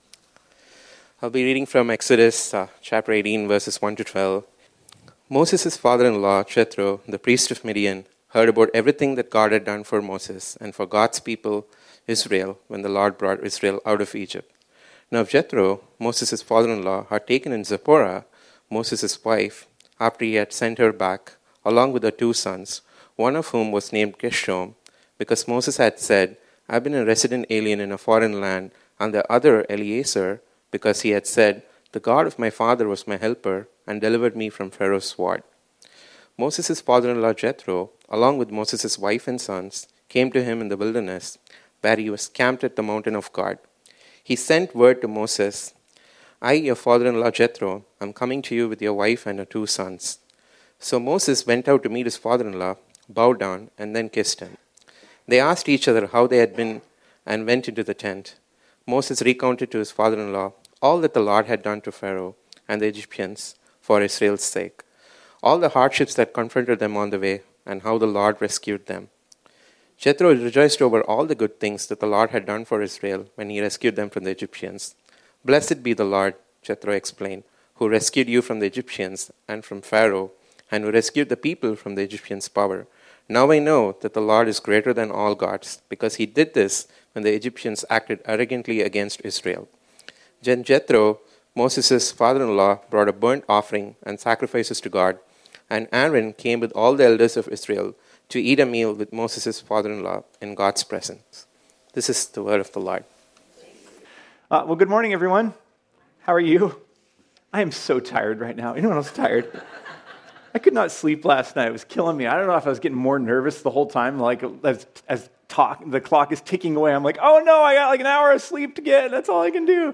This sermon was originally preached on Sunday, August 6, 2023.